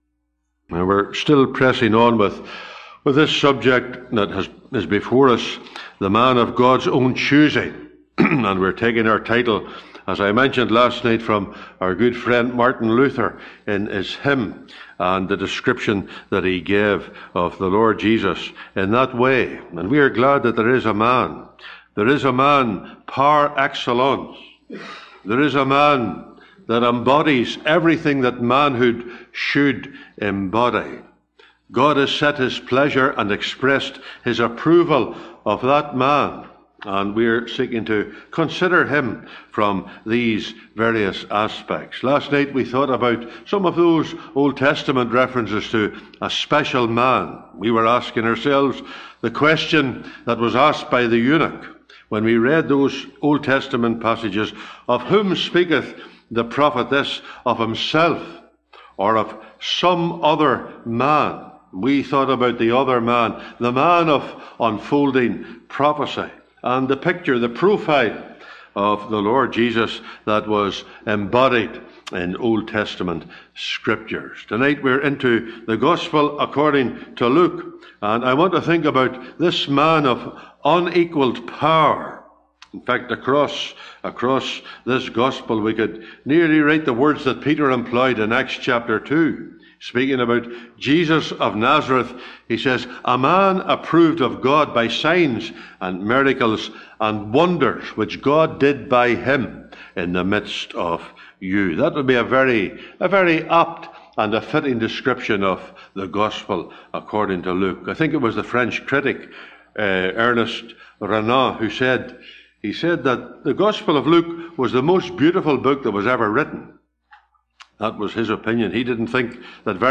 (Recorded in Stark Road Gospel Hall, 9th Sept 2024)